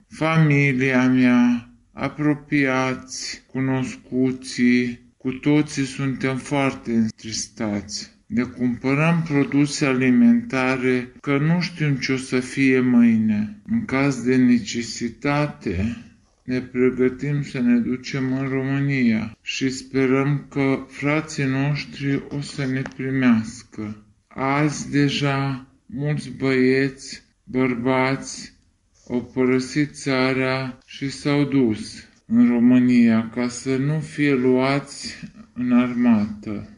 În Solotvino, oraş aflat la graniţă, traiesc numeroşi români. Unul dintre ei a acceptat cu greu să vorbească cu reporterii la Radio România Sighet, cărora le-a cerut să-i distorsioneze vocea: